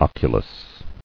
[oc·u·lus]